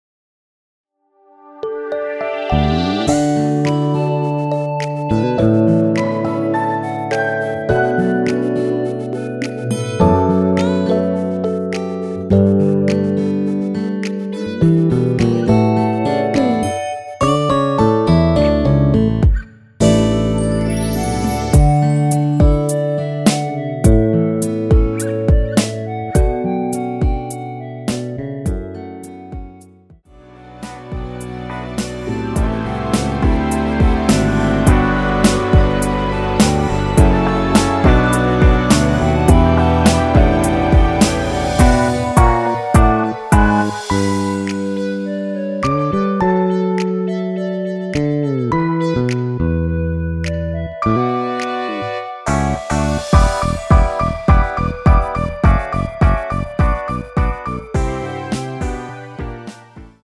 원키1절후 후렴(2절삭제)으로 진행되는 멜로디 포함된 MR 입니다.(미리듣기및 본문 가사 참조)
멜로디 MR이란
앞부분30초, 뒷부분30초씩 편집해서 올려 드리고 있습니다.
중간에 음이 끈어지고 다시 나오는 이유는